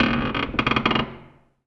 metal_low_creak_squeak_01.wav